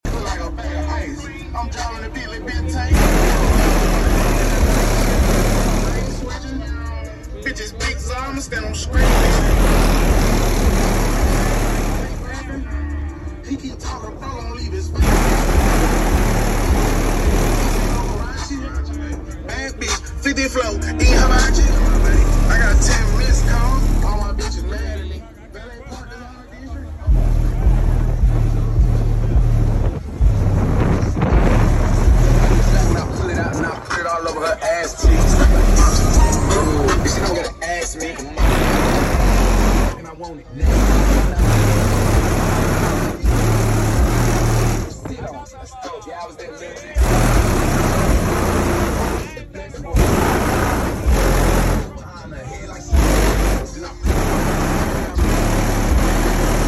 🤯🔊 Trunk Beating Hard LS Sound Effects Free Download